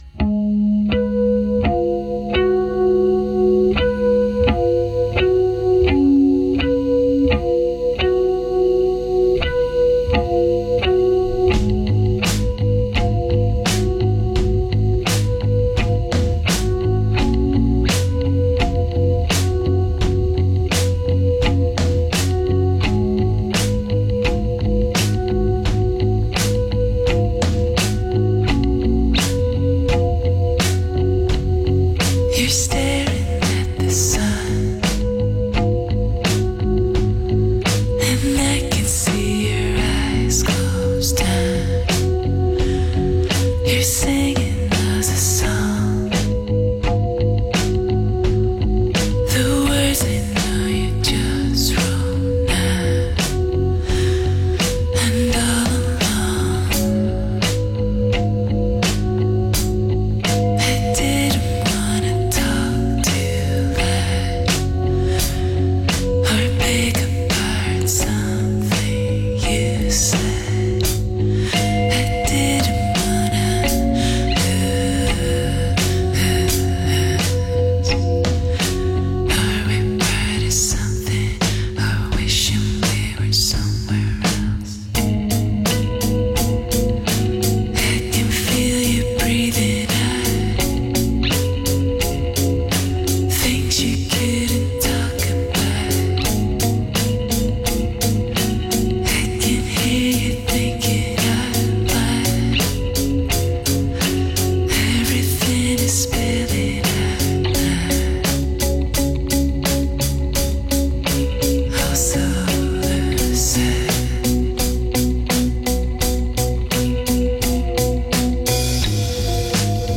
other-worldly voice
atmospheric guitar